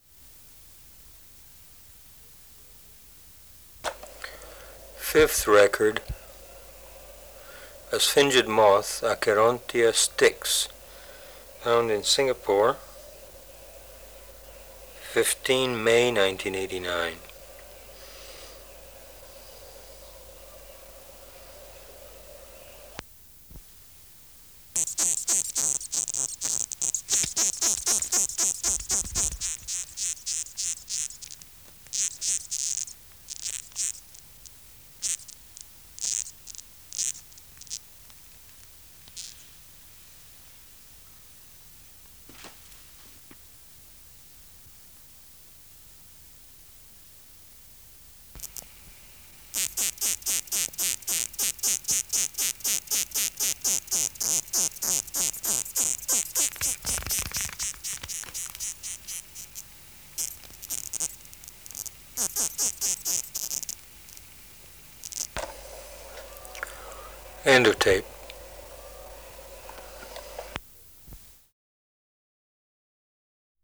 Acherontia styx
Voice Introduction